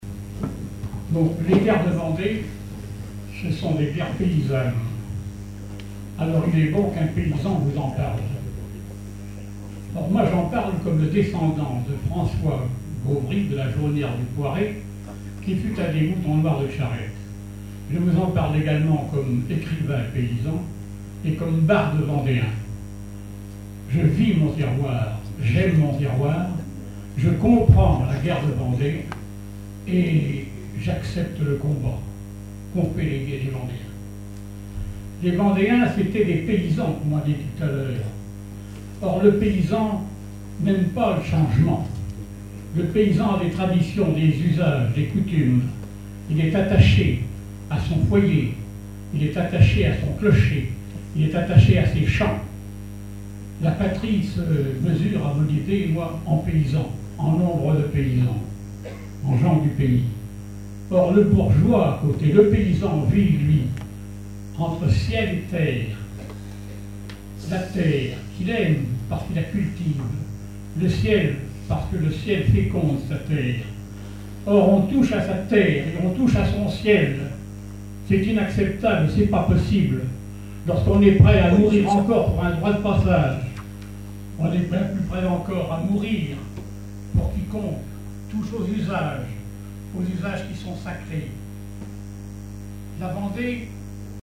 congrès, colloque, séminaire, conférence
Conférence de la Société des écrivains de Vendée
Catégorie Témoignage